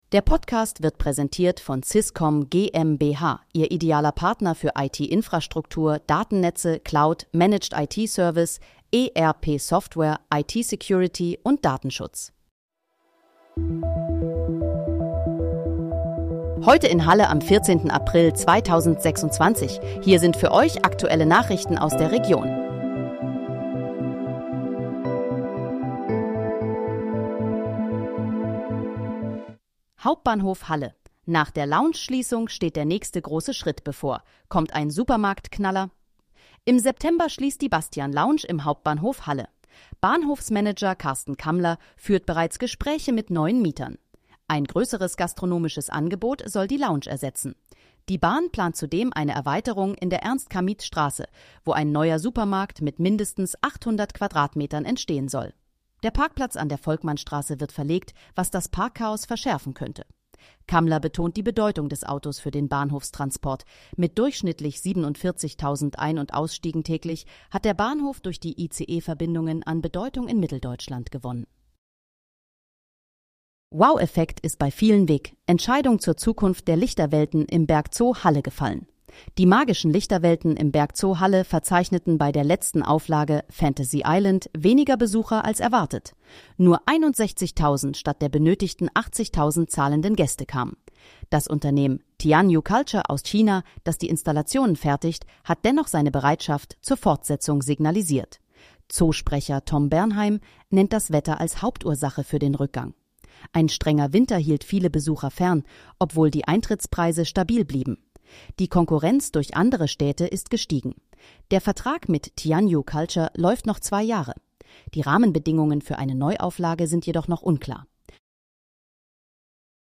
Heute in, Halle: Aktuelle Nachrichten vom 14.04.2026, erstellt mit KI-Unterstützung